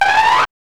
5206R SYN-FX.wav